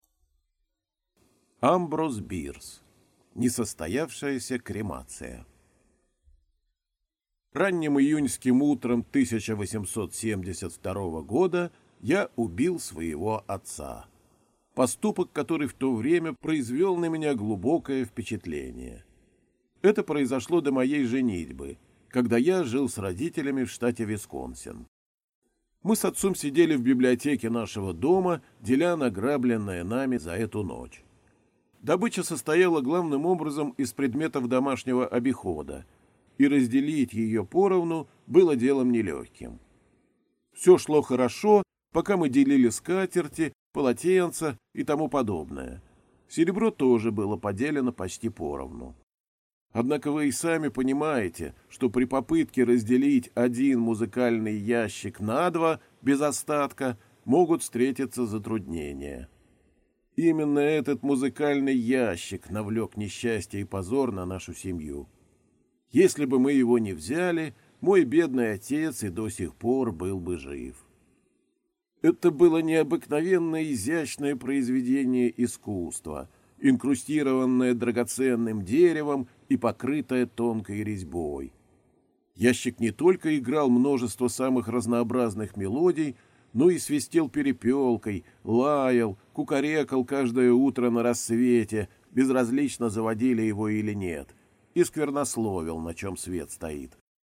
Аудиокнига Несостоявшаяся кремация | Библиотека аудиокниг